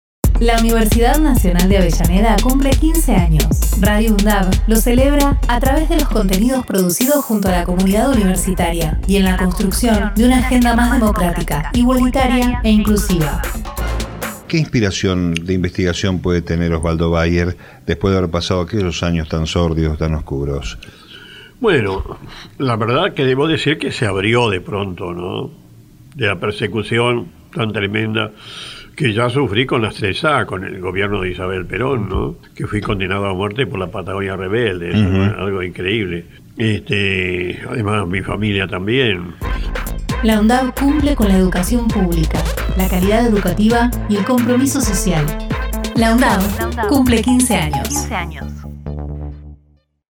Osvaldo Bayer (Archivo Radio UNDAV - 2013)
Spot LaUndavCumple_Bayer.mp3